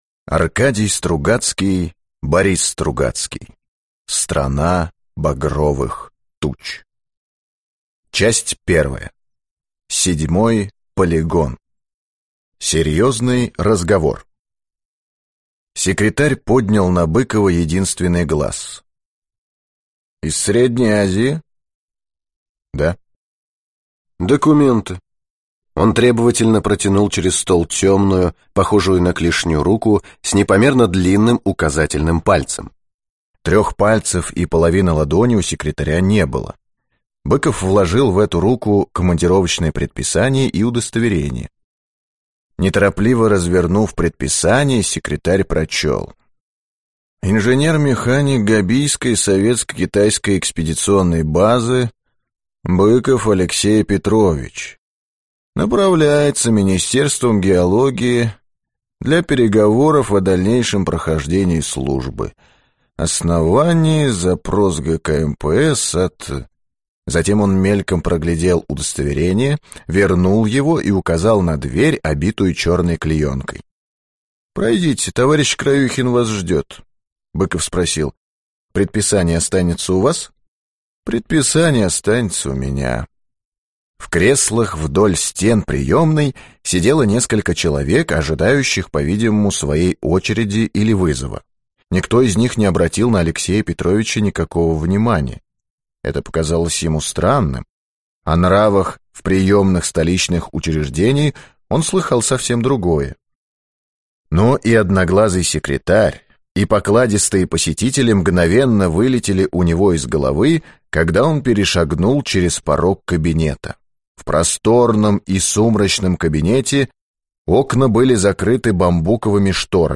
Аудиокнига Страна багровых туч | Библиотека аудиокниг